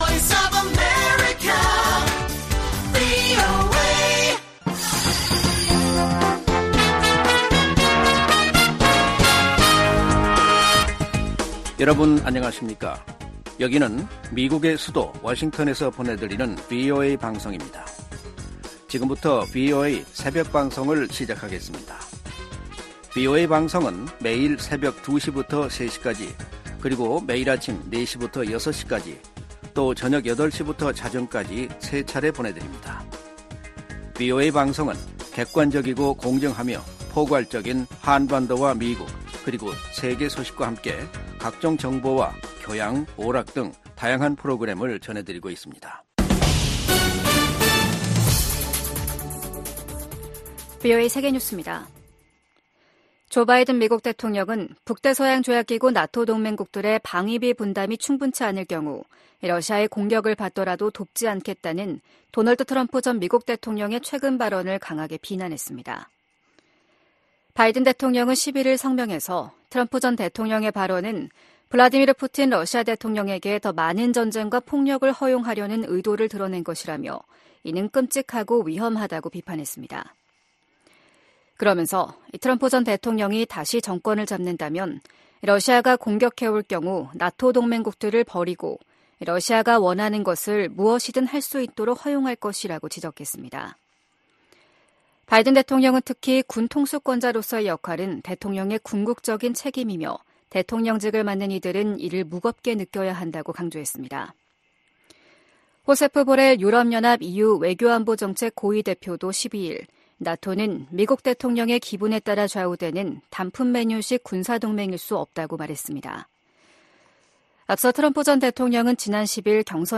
VOA 한국어 '출발 뉴스 쇼', 2024년 2월 13일 방송입니다. 북한 국방과학원이 조종 방사포탄과 탄도 조종체계를 새로 개발하는 데 성공했다고 조선중앙통신이 보도했습니다. 지난해 조 바이든 행정부는 총 11차례, 출범 이후 연간 가장 많은 독자 대북제재를 단행한 것으로 나타났습니다. 미국과 한국·일본의 북한 미사일 경보 정보 공유는 전례 없는 3국 안보 협력의 상징이라고 미 국방부가 강조했습니다.